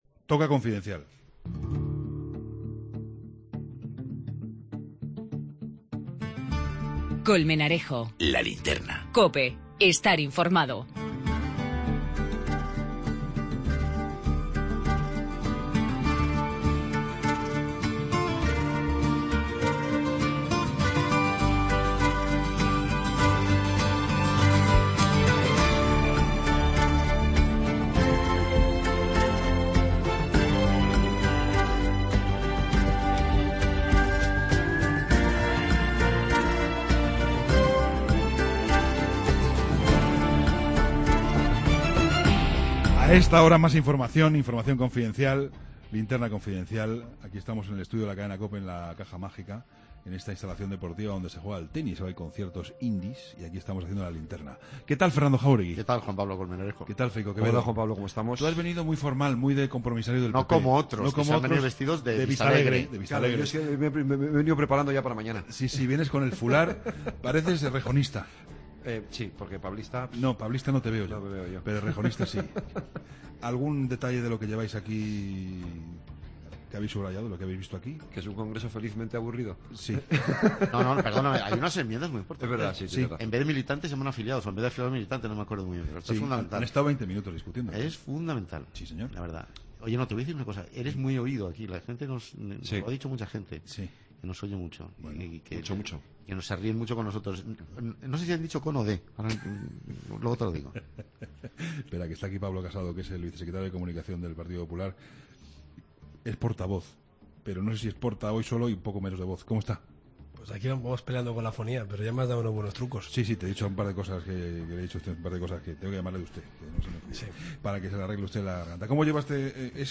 entrevista a Pablo Casado, vicesecretario de Comunicación del Partido Popular.